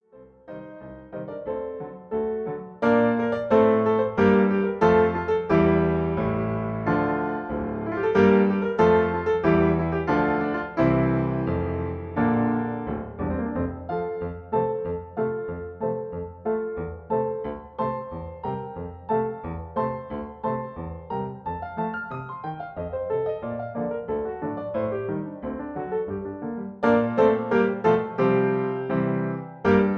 MP3 piano accompaniment